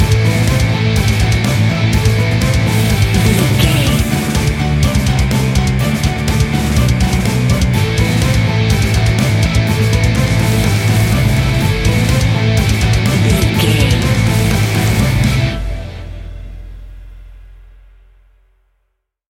Epic / Action
Fast paced
Aeolian/Minor
Fast
hard rock
guitars
instrumentals
Heavy Metal Guitars
Metal Drums
Heavy Bass Guitars